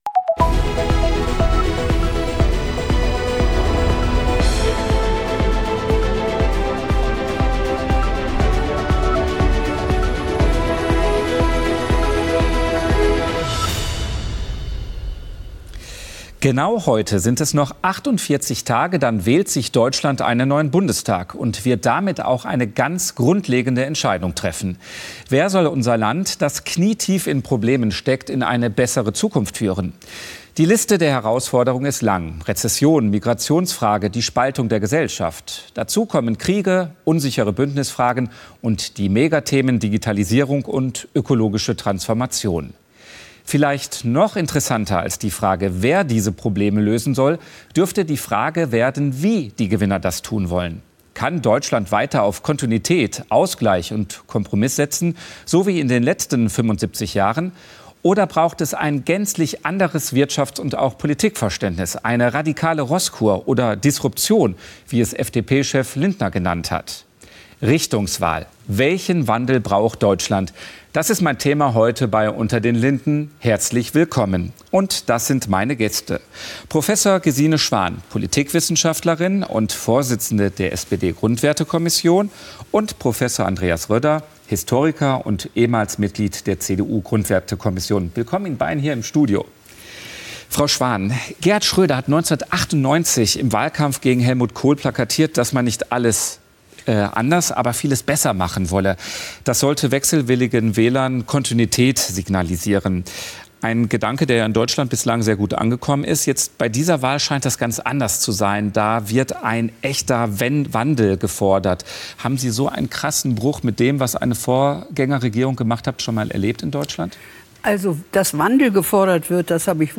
„unter den linden“ ist das politische Streitgespräch bei phoenix.
Die Diskussionen sind kontrovers, aber immer sachlich und mit ausreichend Zeit für jedes Argument.